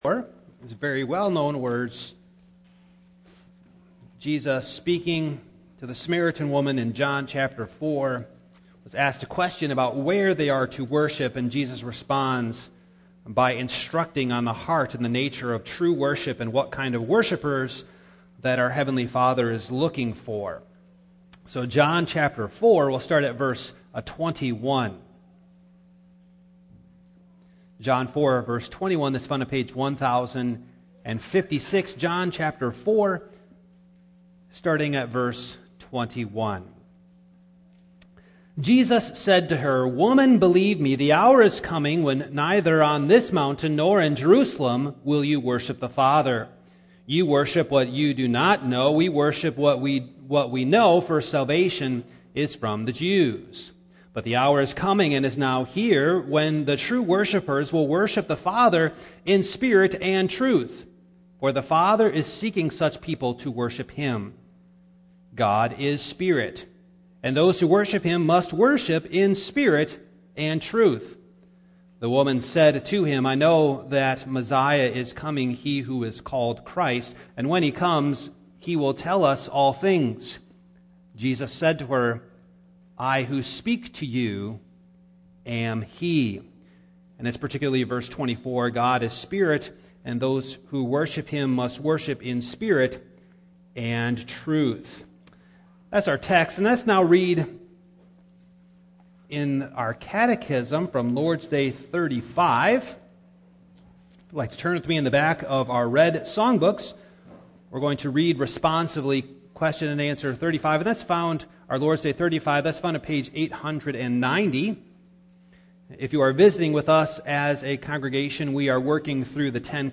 Passage: John 4:21-26 Service Type: Morning